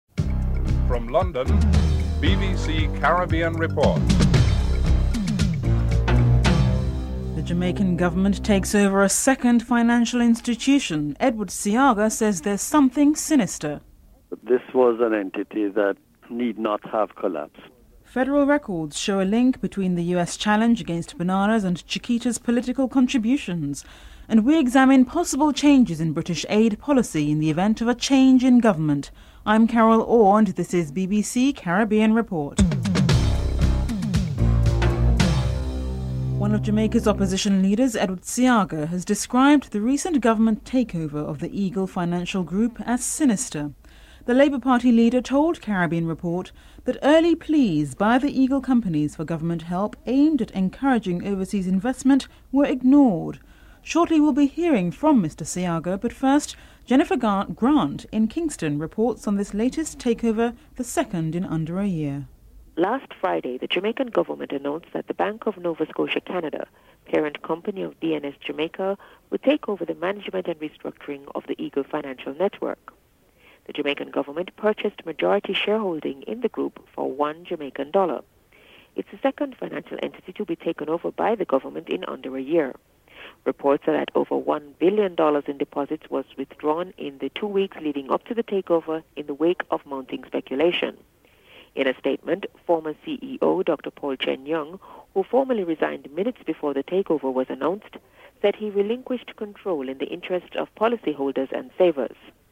1. Headlines (00:00-00:35)
Edward Seaga, Leader of the Opposition Labour Party is interviewed (02:43-06:38)